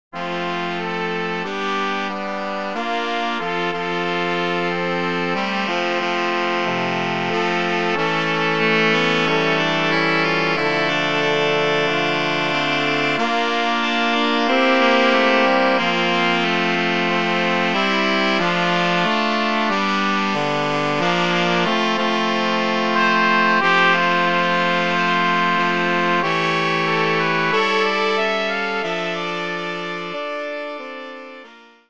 Opracowanie na kwintet dęty.